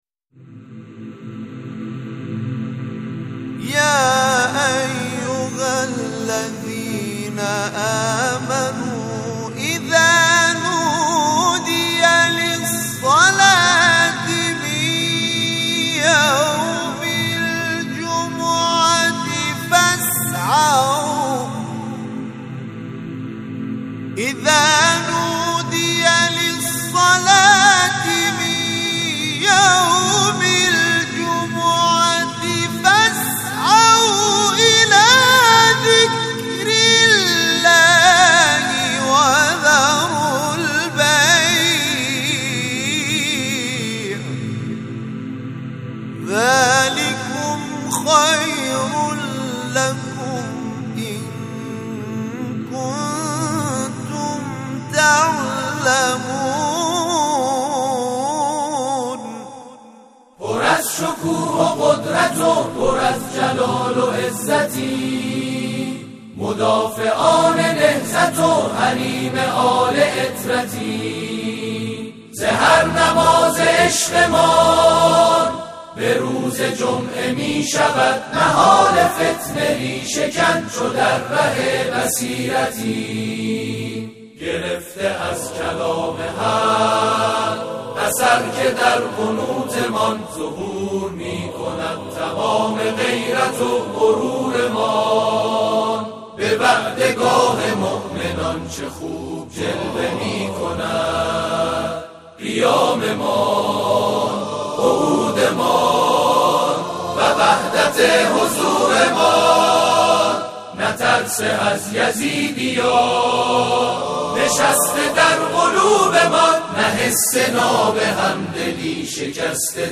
اجرای بزرگترین گروه تواشیح کشور در نماز جمعه
گروه فعالیت‌‌های قرآنی: گروه همخوانی و تواشیح محمد رسول الله(ص) تهران، 15 بهمن‌ماه به اجرای برنامه در نماز جمعه تهران پرداخت.
این گروه تواشیح که از لحاظ تعداد اعضا، بزرگ‌ترین گروه تواشیح در کشور و متشکل از 13 گروه تواشیح تهران است در مدت کوتاهی که از عمر آن می‌گذرد به پای ثابت اجرای برنامه در مراسم‌ها در مناسبت‌های مختلف تبدیل شده است.
آخرین اجرای این گروه نیز اجرای سرود «نماز جمعه» در نماز جمعه این هفته تهران بود که در ادامه متن و صوت این اجرا آمده است.